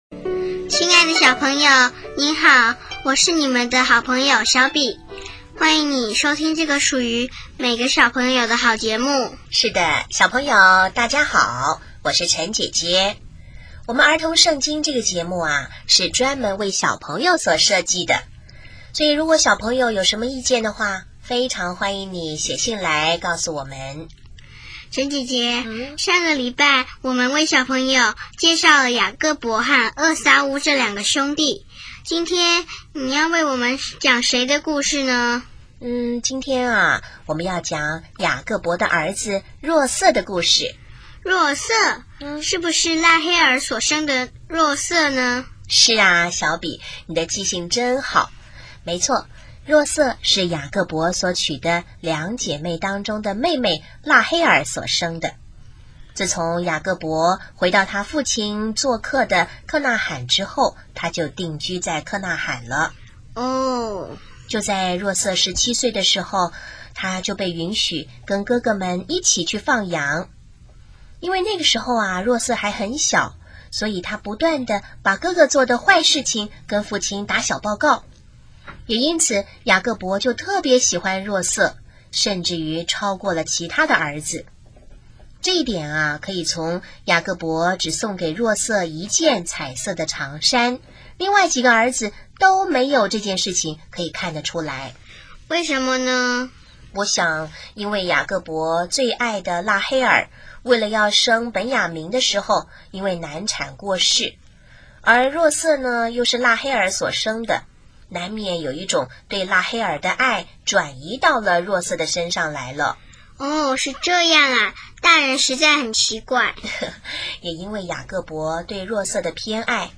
【儿童圣经故事】12|若瑟(一)卖往埃及